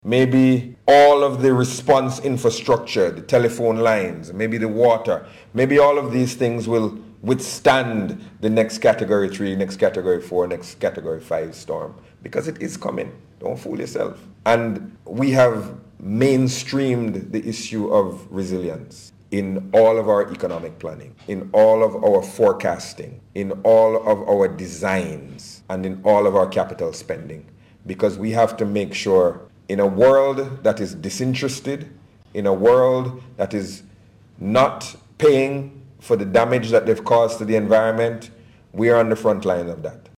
He was at the time speaking at the launch of the Beryl Emergency Resilient Recovery (BERRy) Business Grant.